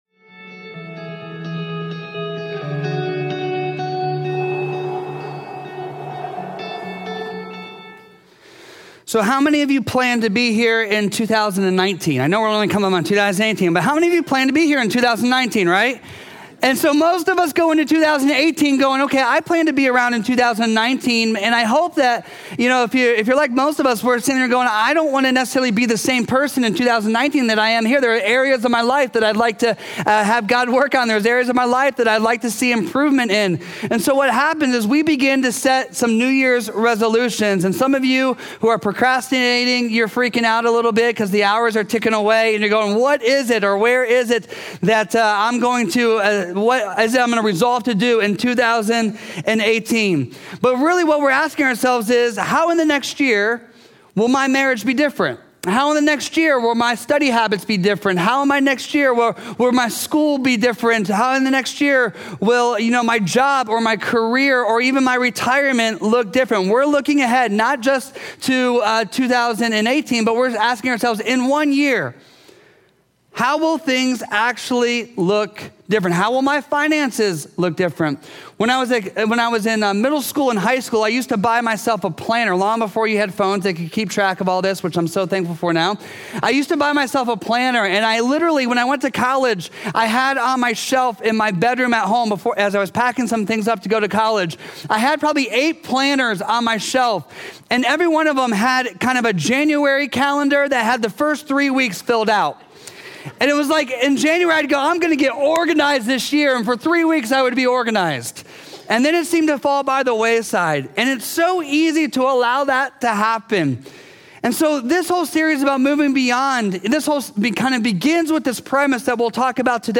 A message from the series "Believe and Have Life."